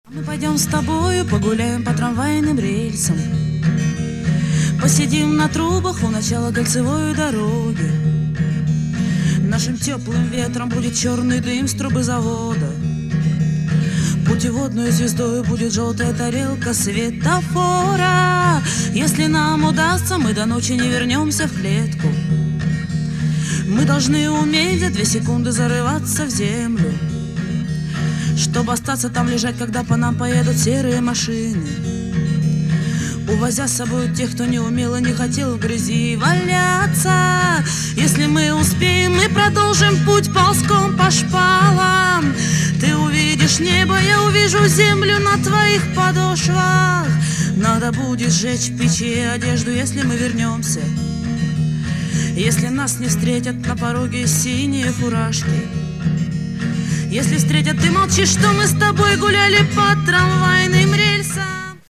• Качество: 128, Stereo
гитара
женский вокал
грустные
русский рок
психоделический рок
акустическая гитара